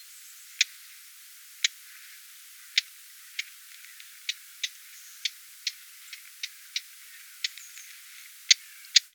SUMPFROHRSÄNGER, Alarm !!!